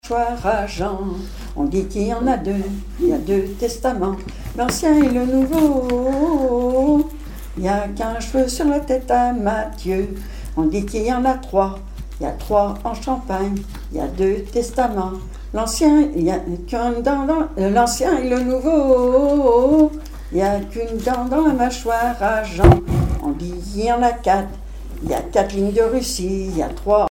Genre énumérative
Chansons et commentaires
Pièce musicale inédite